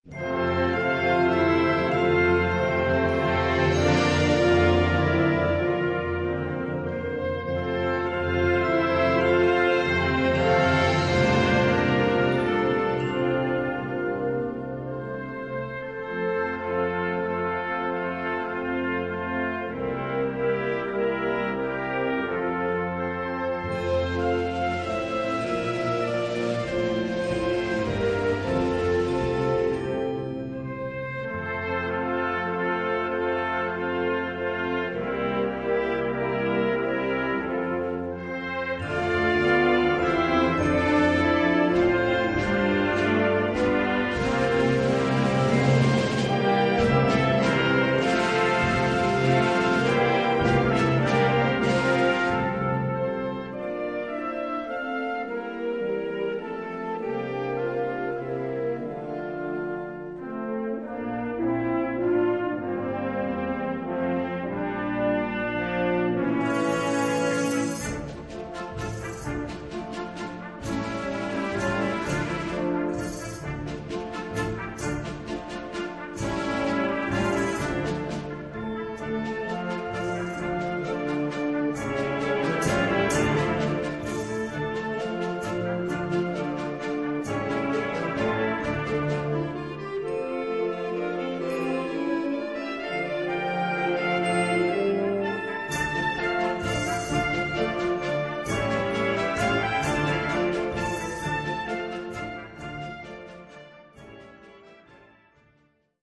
Gattung: Variationen
4:11 Minuten Besetzung: Blasorchester Zu hören auf